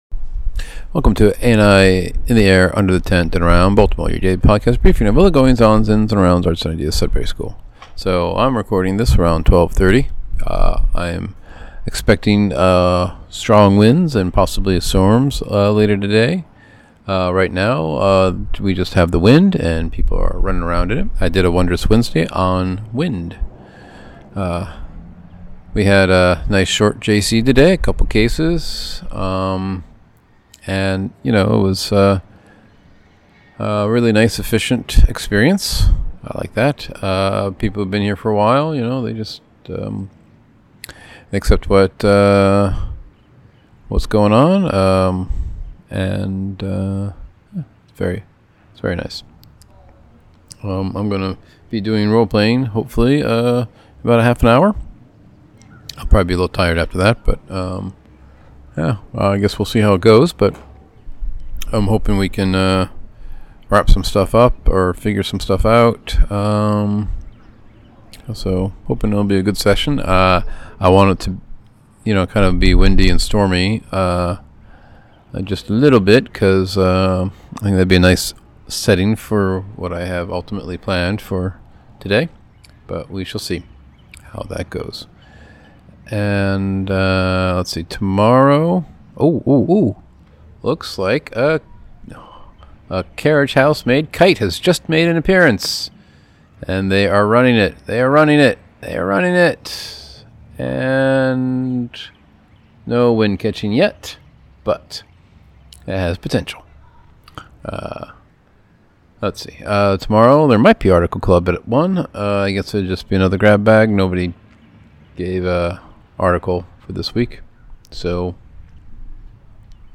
Recording at 12:30, expecting strong winds and storms.